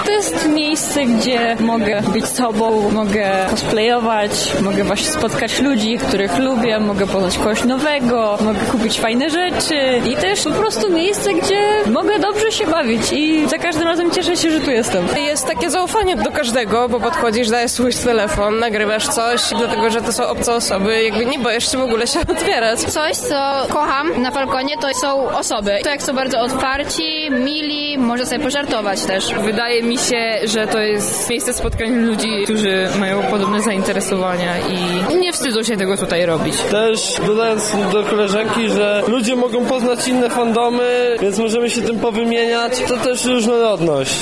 Zapytała uczestników o ich refleksje związane z wydarzeniem:
Falkon 2026, relacja
Uczestniki-Falkon_01.mp3